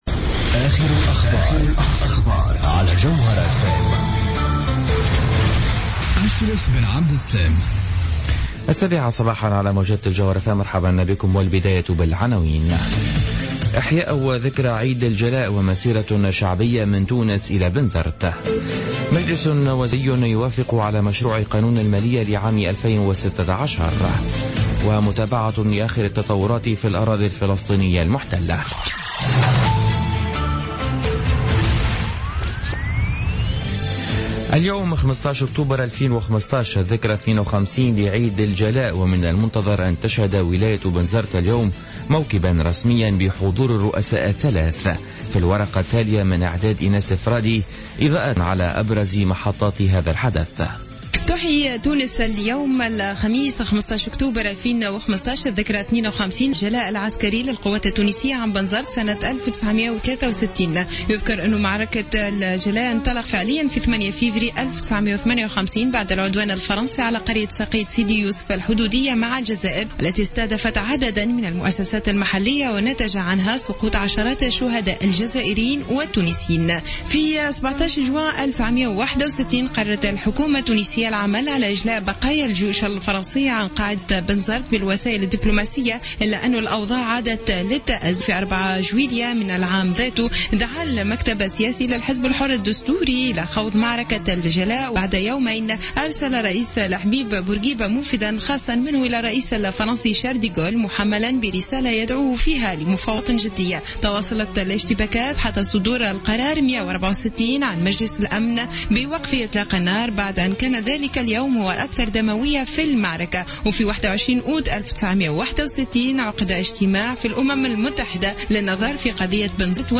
نشرة أخبار السابعة صباحا ليوم الخميس 15 أكتوبر 2015